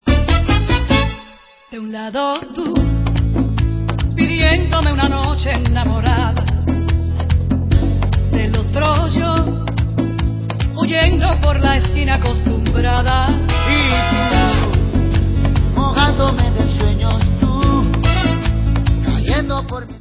sledovat novinky v oddělení World/Latin